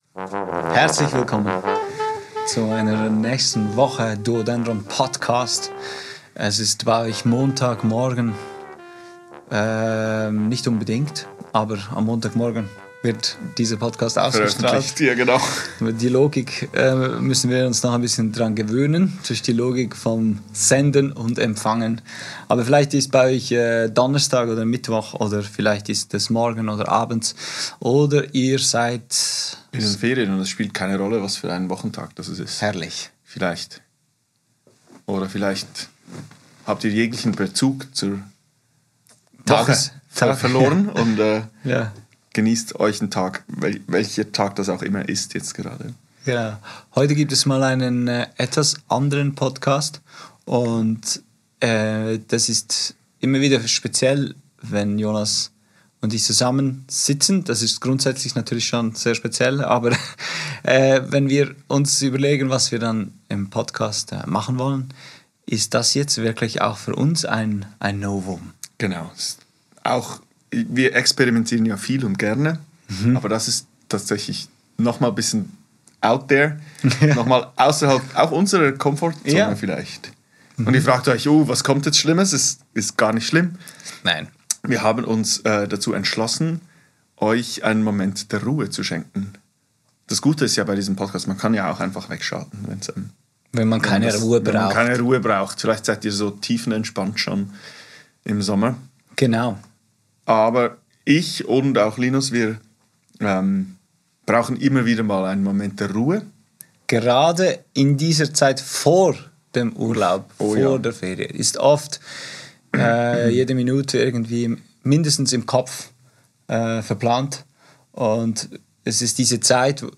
Ist das noch Musik à la John Cage's 4'33 oder schon eine Meditationsübung? Was klar ist: Stille ist heute oft rar und wir möchten euch gerne dazu anregen, öfters diese Absenz von Inputs zu suchen und einfach im Moment zu verweilen.